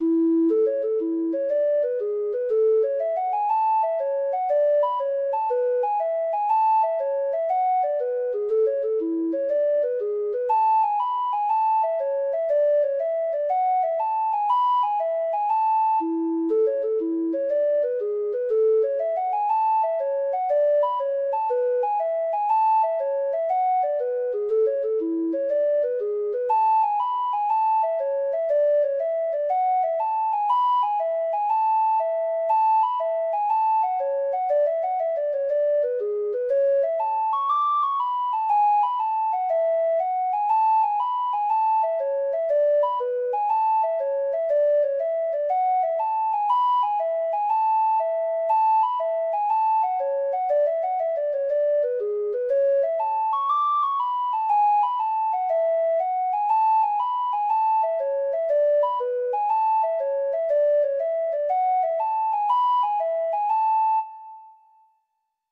Traditional Trad. Spellan's Delight (Irish Folk Song) (Ireland) Treble Clef Instrument version
Traditional Music of unknown author.
Irish